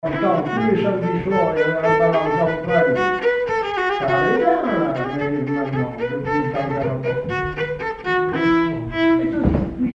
Commentaire et essais
Catégorie Témoignage